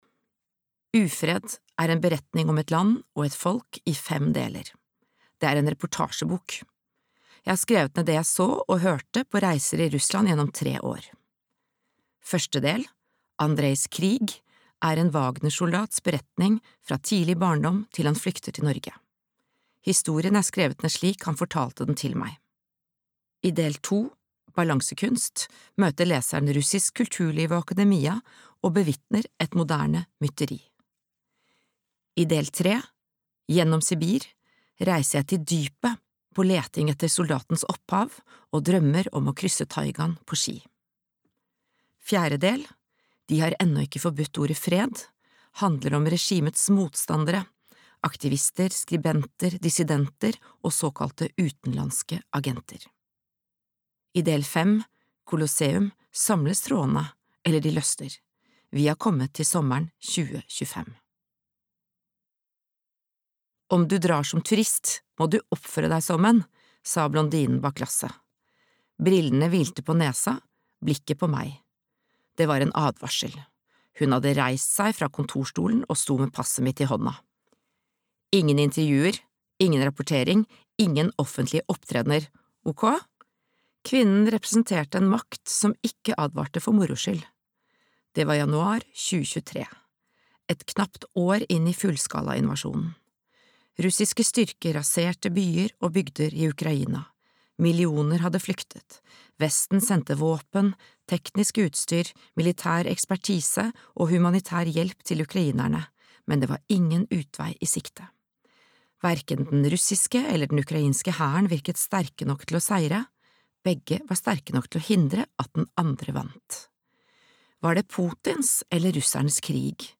Ufred - Russland fra innsiden (lydbok) av Åsne Seierstad